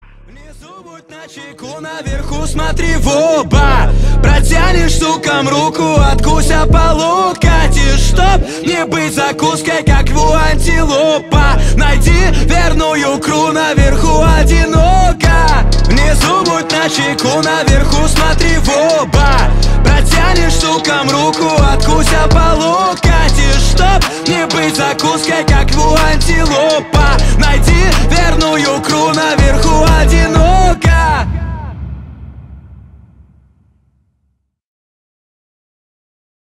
• Качество: 320, Stereo
Хип-хоп
качающие